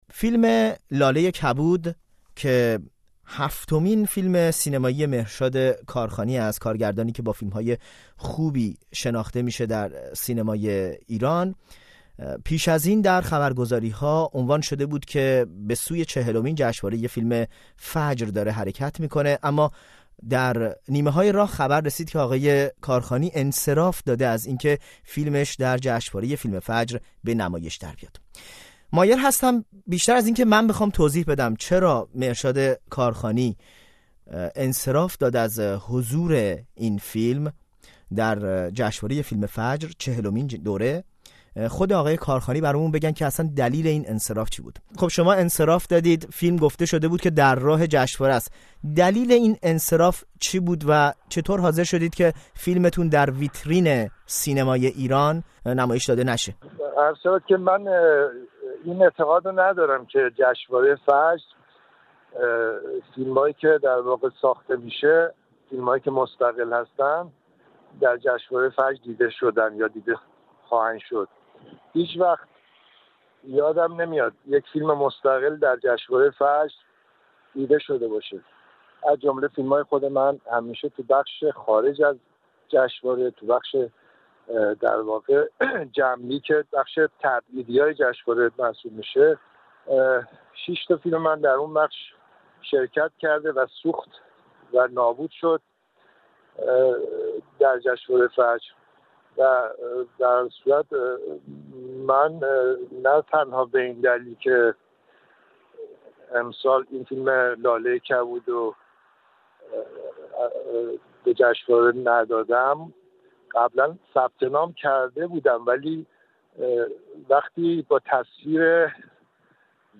در این باره با او گفت‌وگو کرده است.